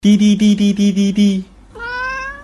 короткие
мяуканье
звуки кота